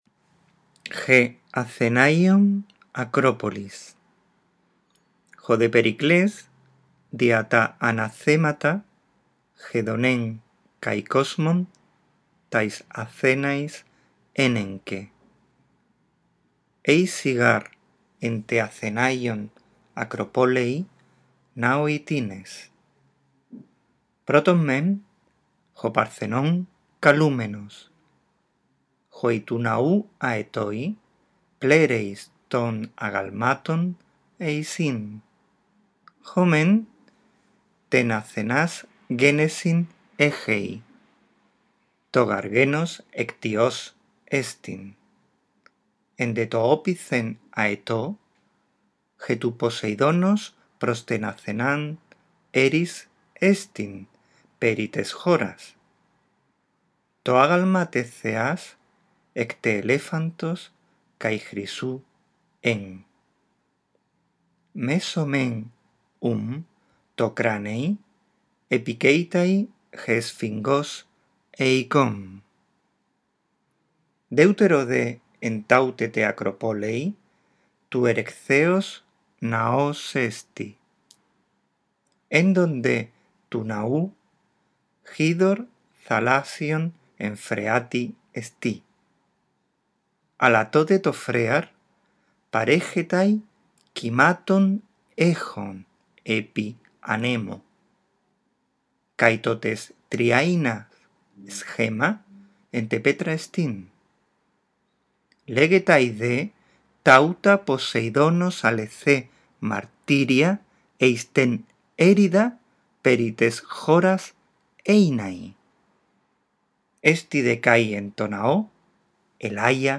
Procura imitar la entonación y captar el significado general.